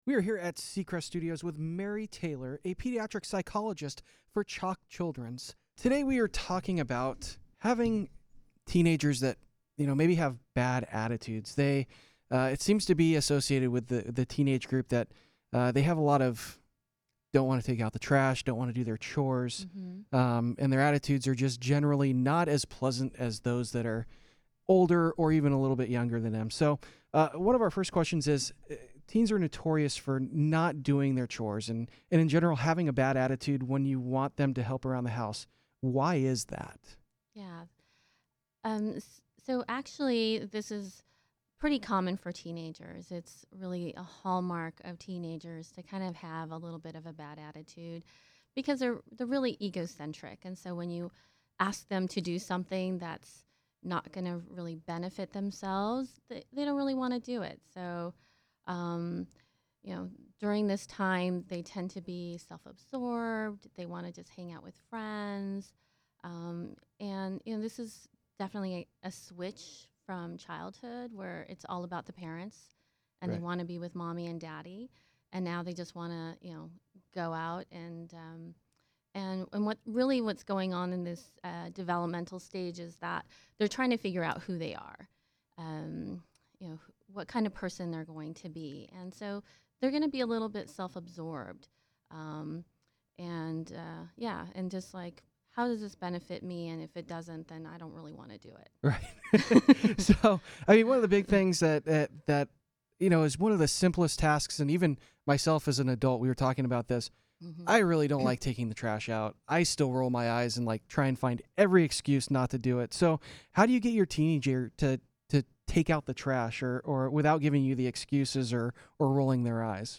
A poor attitude is a hallmark of teenagers, a CHOC pediatric psychologist tells CHOC Radio.